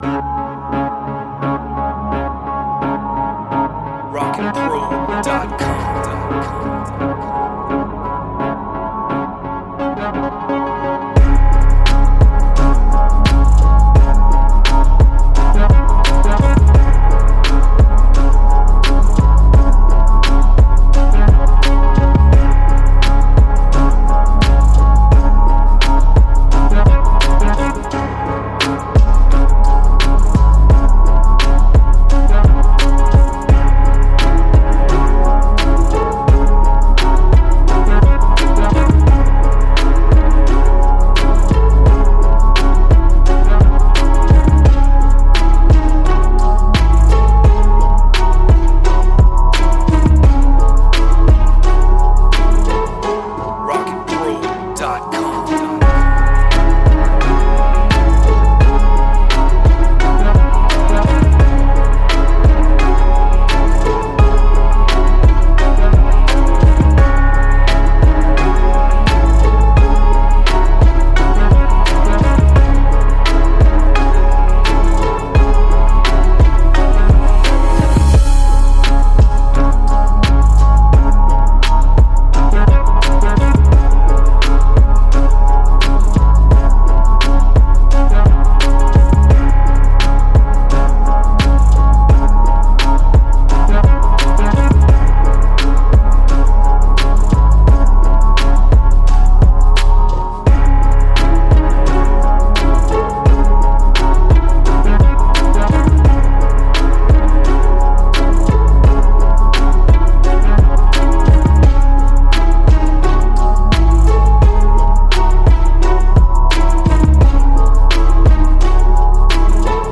86 BPM. Dark rap beat with haunting strings and pizz plucks.
Trap